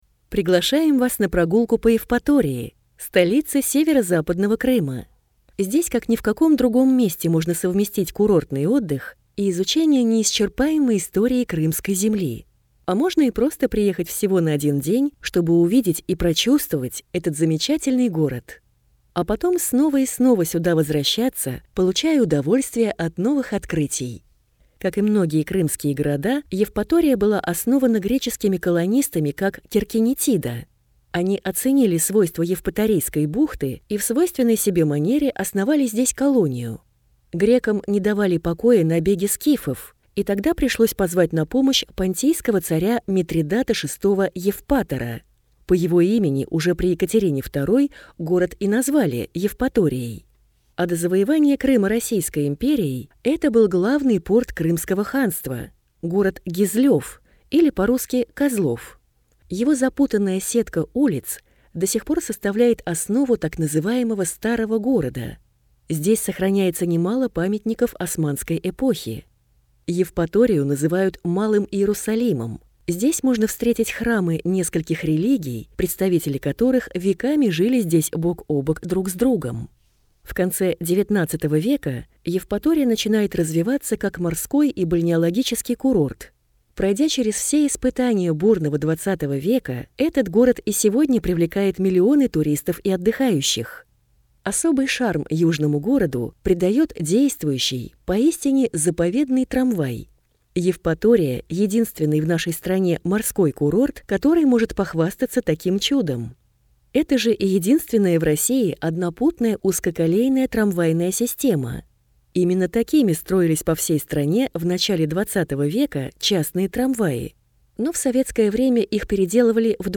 Аудиокнига Евпатория. Аудиогид | Библиотека аудиокниг